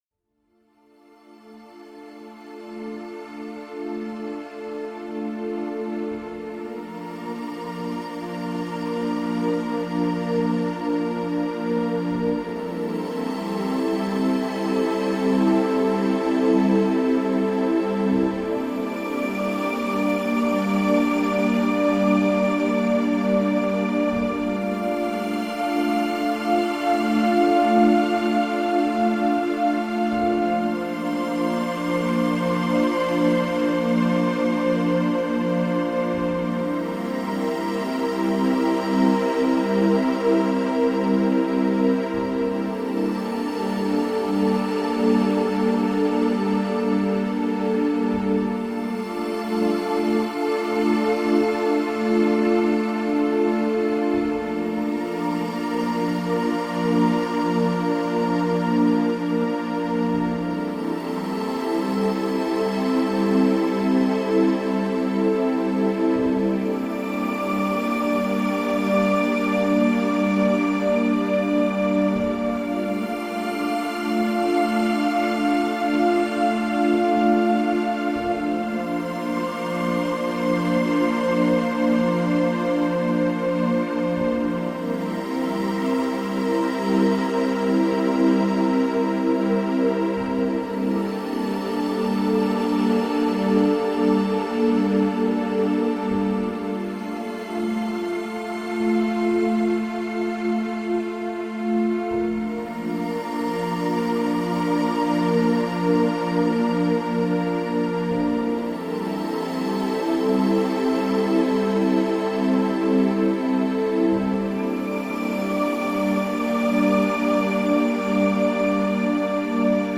CHANTS VIBRATOIRES
Cell-Nerve-Regeneration-✤-Inner-Body-Self-Healing-Meditation-✤-SH-012.mp3